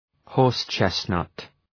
Προφορά
{,hɔ:rs’tʃestnʌt}